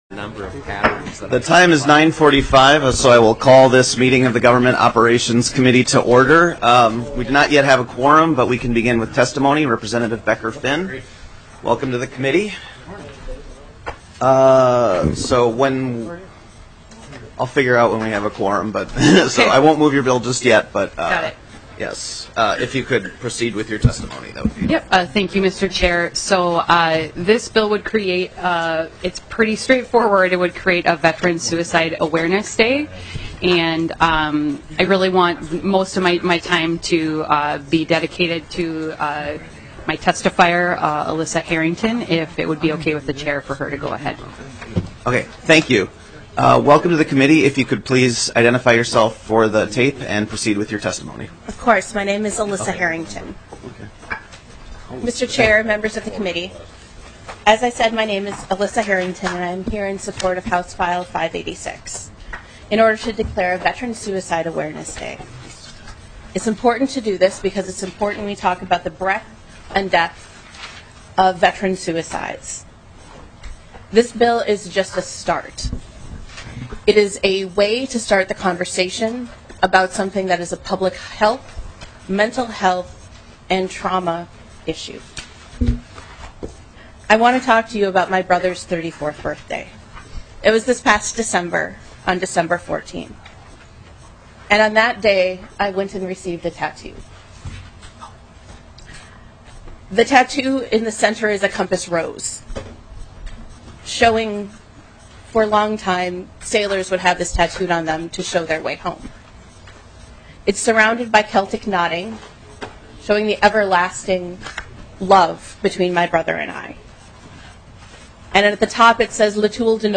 House Transportation Finance and Policy Division - evening meeting